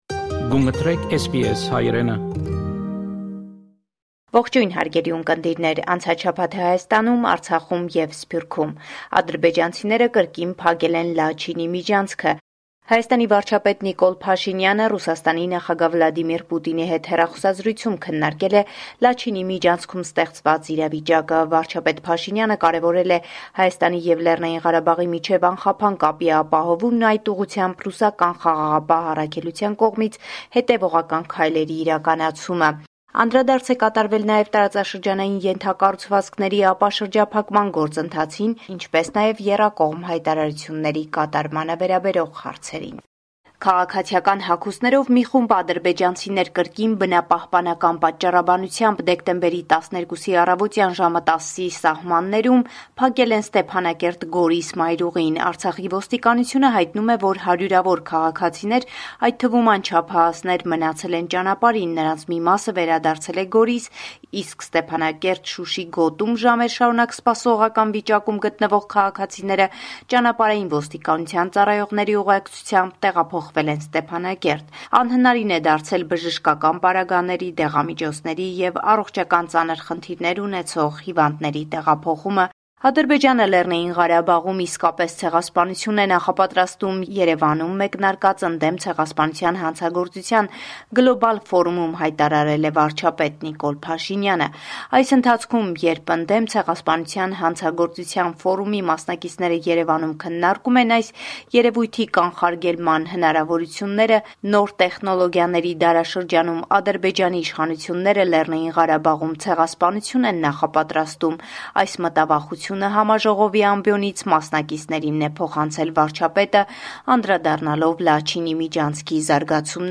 Latest News from Armenia – 13 December 2022